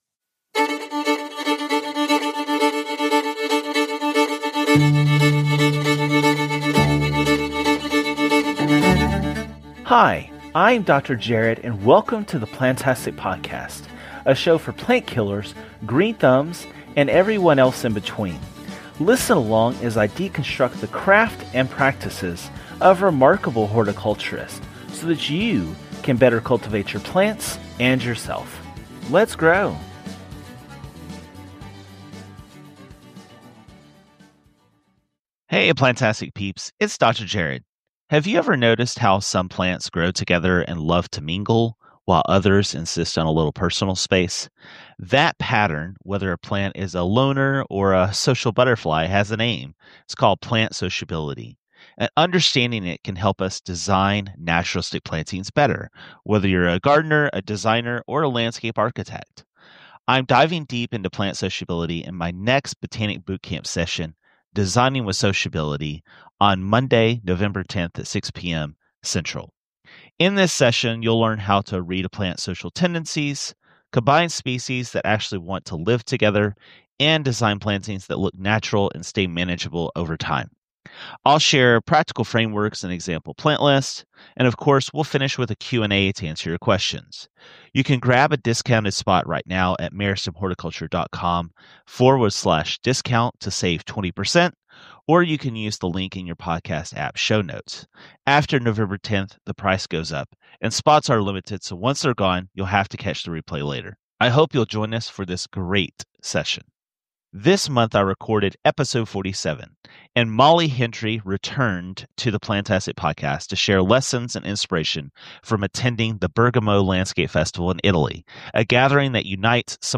Today, Jay sits down with Grammy Award–winning artist Cardi B for a rare and intimate conversation that reveals the woman behind the spotlight.